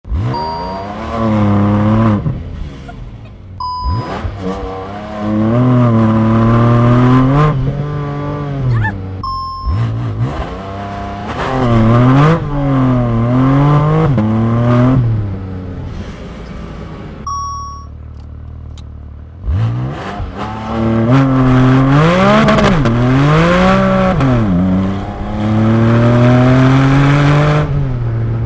Porsche 0 100km/h sound effects free download